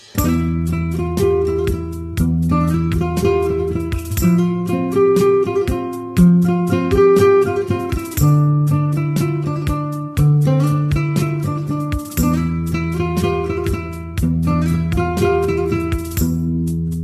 صدای زنگ غمگین